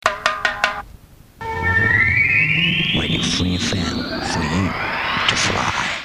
Genre Jingle